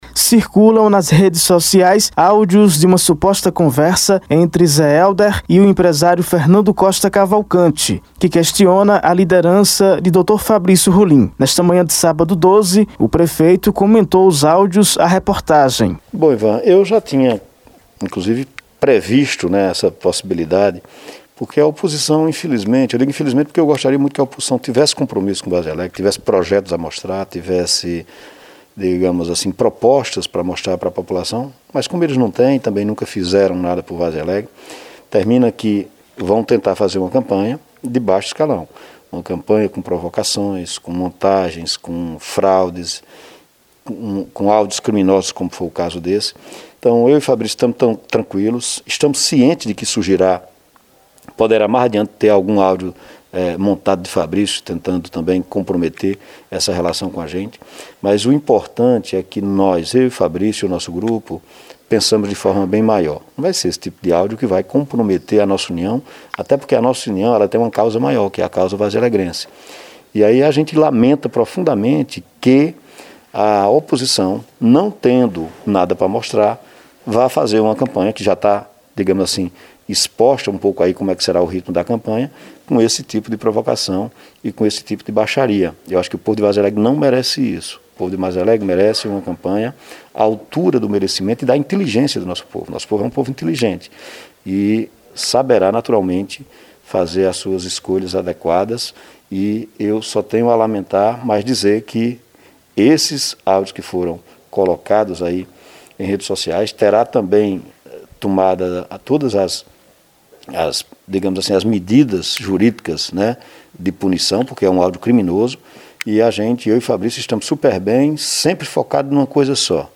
Nesta manhã de sábado, 12, o prefeito comentou os áudios a reportagem.